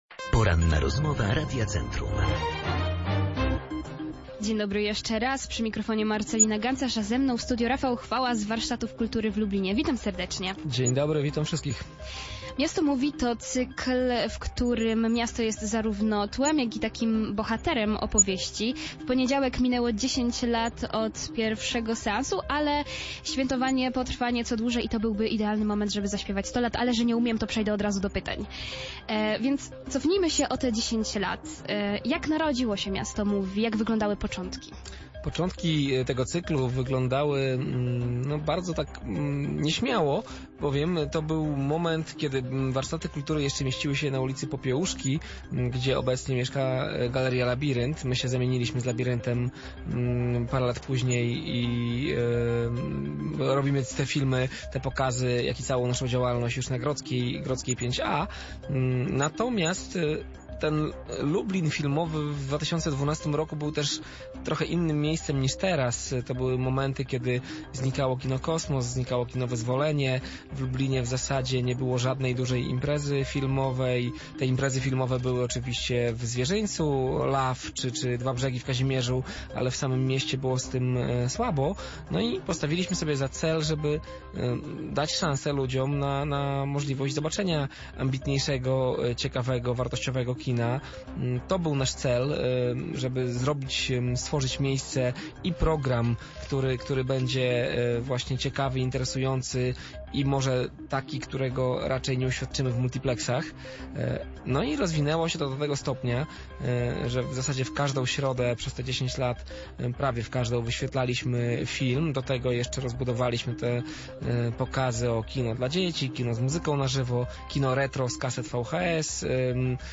10 lat minęło jak jeden dzień. Miasto Movie to cykl, który jest znany lubelskim kinomanom, o jego początkach rozmawialiśmy podczas Porannej Rozmowy Radia Centrum.
O wspominania związane z wydarzeniem zapytaliśmy naszego gościa.